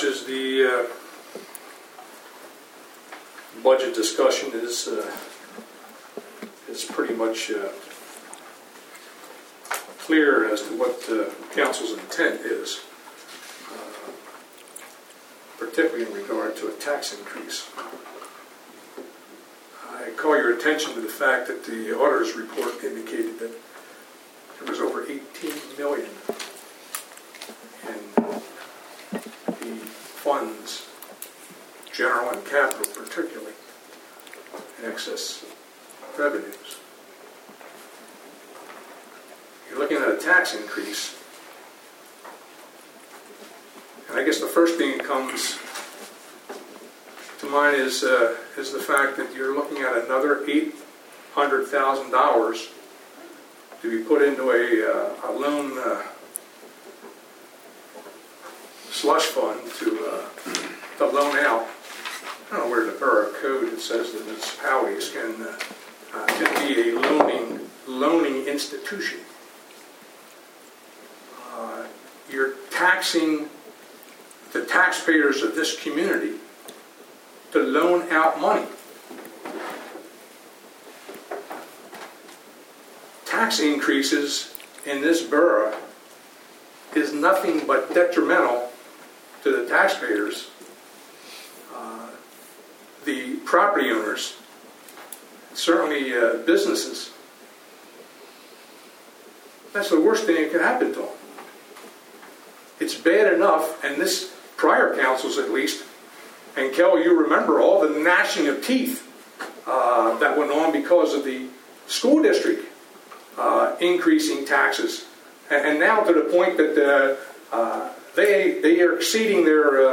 During the meeting, several citizens spoke about issues on the preliminary agenda.